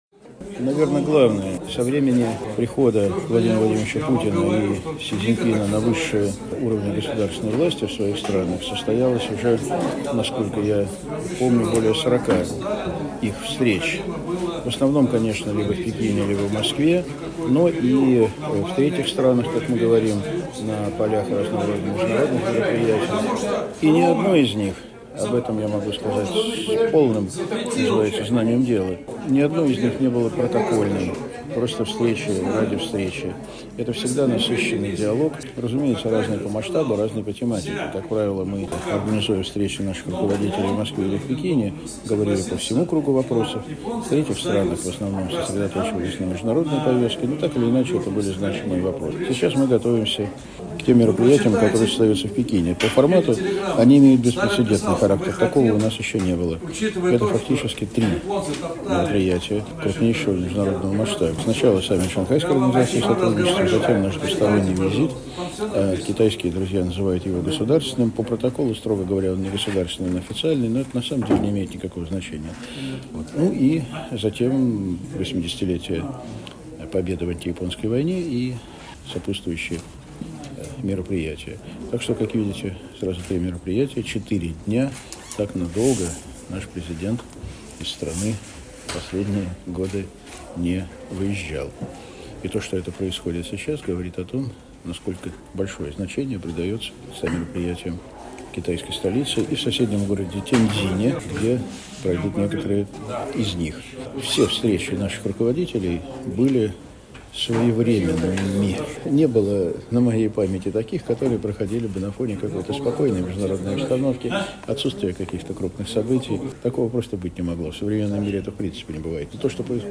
Первый заместитель председателя Комитета Совета Федерации по международным делам Андрей Денисов в интервью журналу «Международная жизнь» рассказал о предстоящем визите Владимира Путина в Китай: